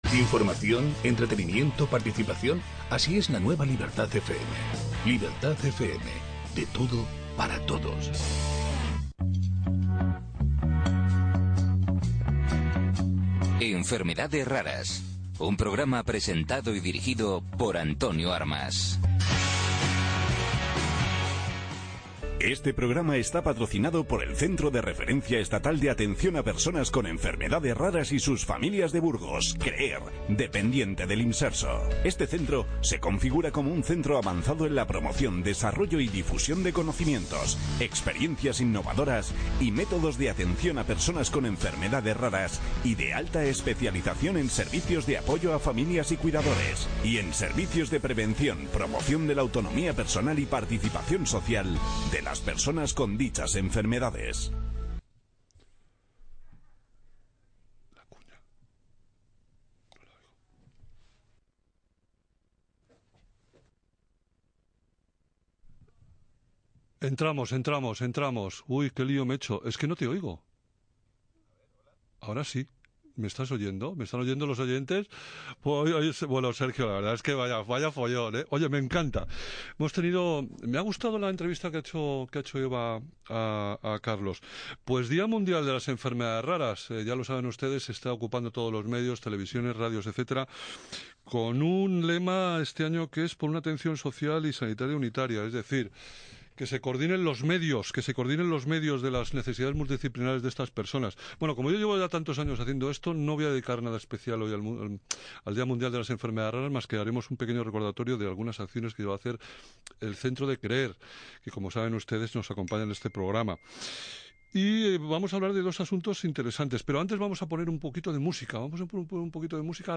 El programa de radio ‘Enfermedades Raras’ del 28 de febrero de 2019